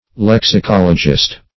Lexicologist \Lex`i*col"o*gist\
(l[e^]ks`[i^]*k[o^]l"[-o]*j[i^]st), n.